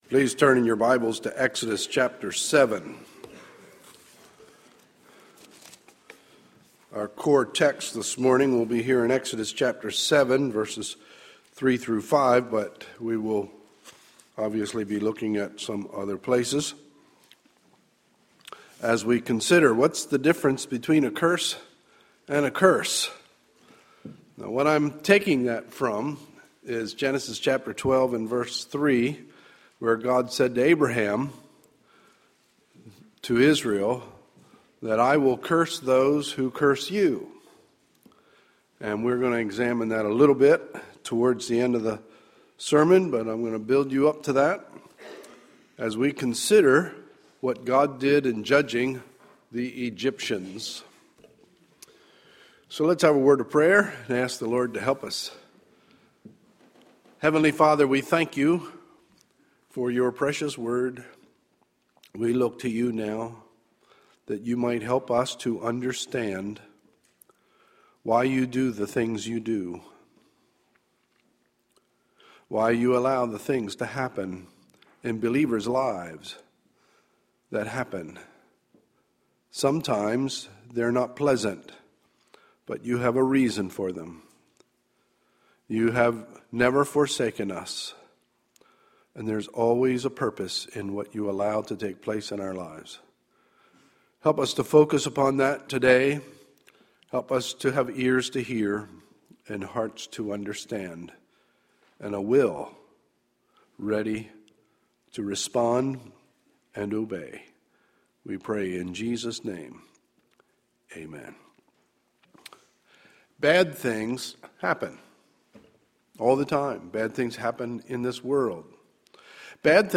Sunday, March 10, 2013 – Morning Message